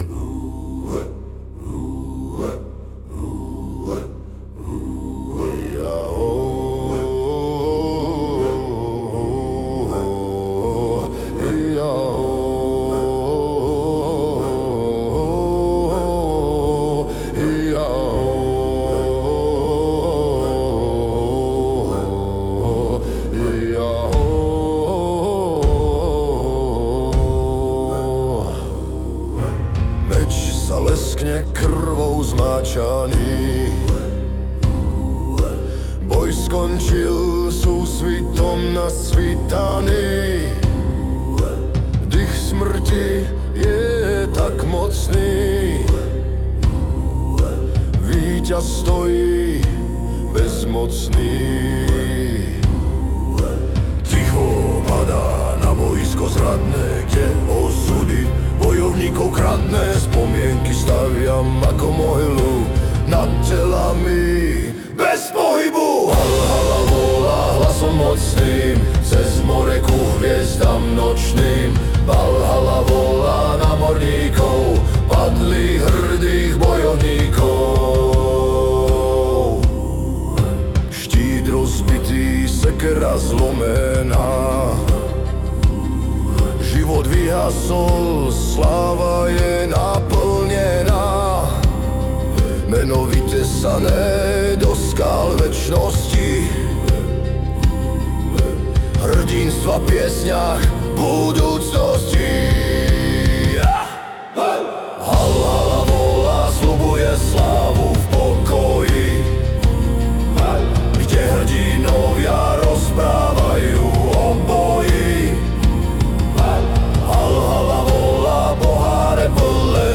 Zní to jako vydařený hymnus*